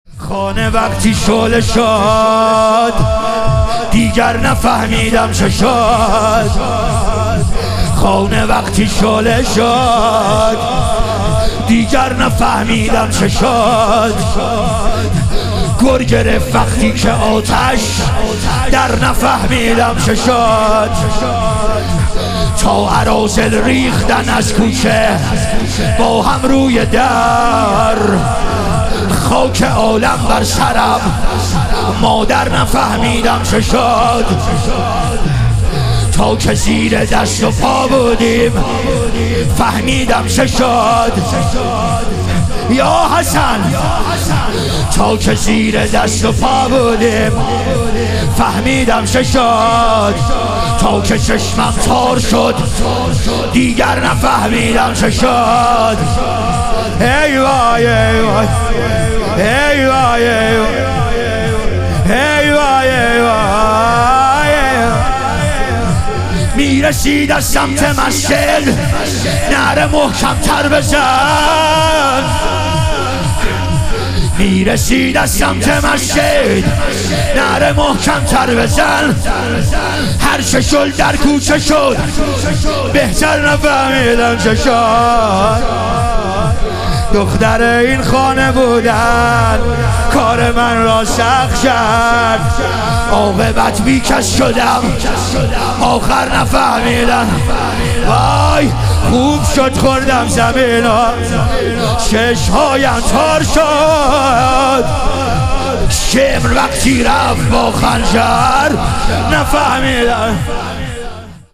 ایام فاطمیه دوم - لطمه زنی